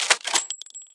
Media:RA_Shelly_Evo.wav UI音效 RA 在角色详情页面点击初级、经典和高手形态选项卡触发的音效